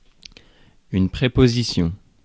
Ääntäminen
Ääntäminen France Tuntematon aksentti: IPA: /pʁe.pɔ.zi.sjɔ̃/ Haettu sana löytyi näillä lähdekielillä: ranska Käännös 1. предлог {m} (predlóg) Suku: f .